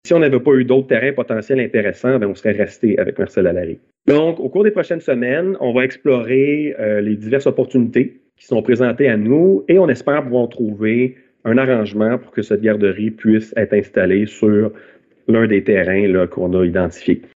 Le maire, Ludovic Grisé-Farand, a confirmé à la séance du conseil du 22 mars, que de nouvelles opportunités de terrains lui avaient été offertes dernièrement.